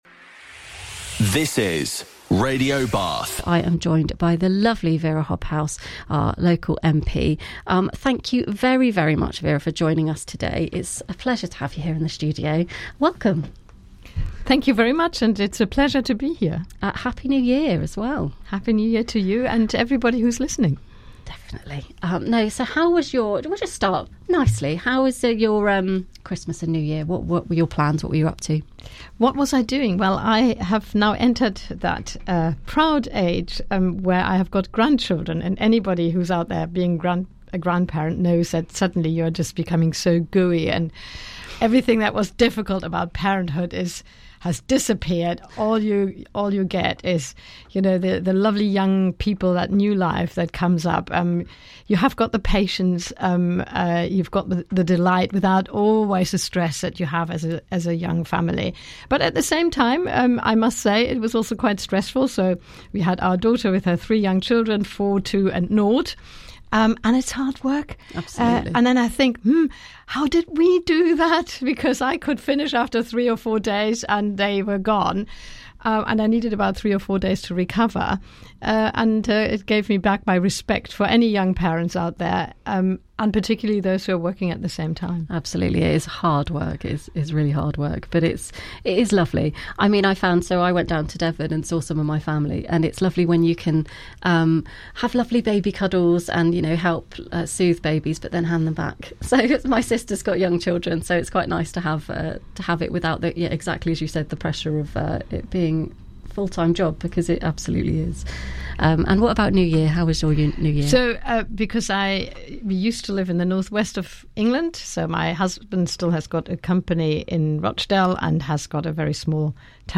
What makes Bath so special at Christmas, and what keeps its communities strong all year round? In a warm and thoughtful conversation with Radio Bath, Bath MP Wera Hobhouse reflects on life, creativity, politics and the importance of being kind to ourselves and each other.